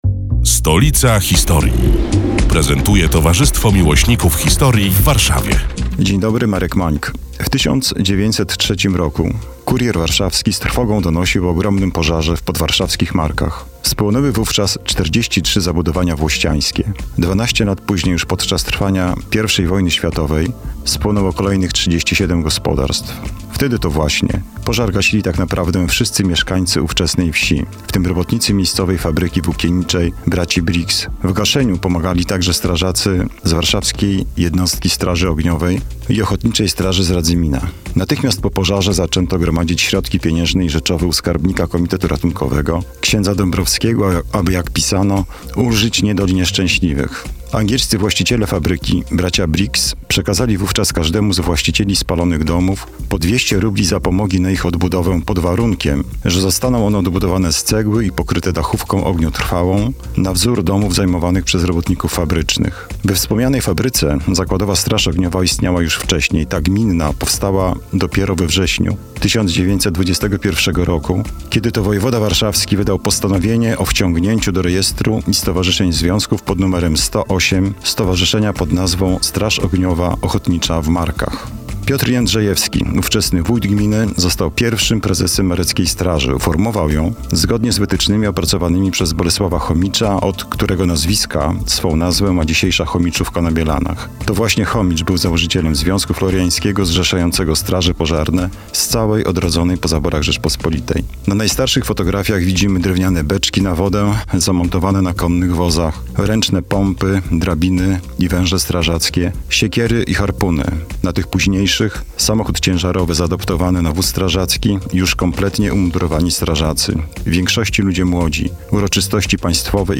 99. felieton pod wspólną nazwą: Stolica historii. Przedstawiają członkowie Towarzystwa Miłośników Historii w Warszawie, które są już od trzech lat emitowane w każdą sobotę, w nieco skróconej wersji, w Radiu Kolor.